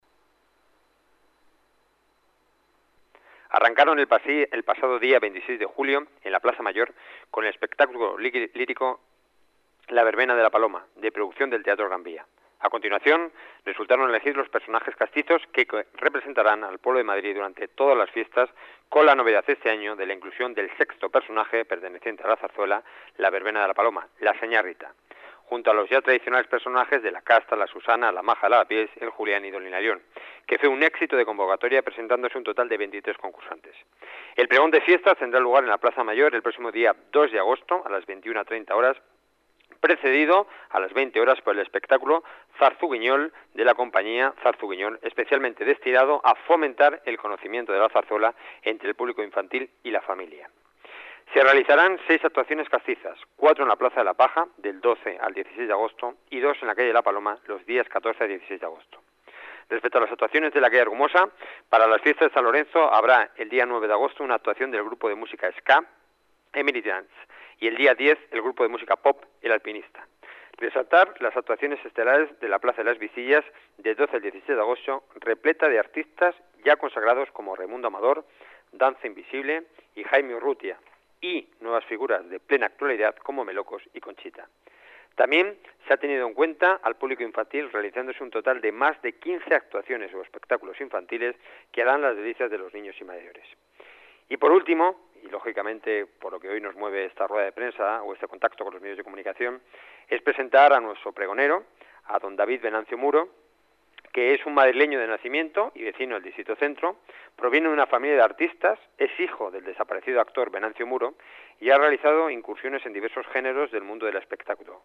Nueva ventana:Enrique Núñez, concejal de Centro